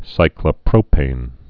(sīklə-prōpān)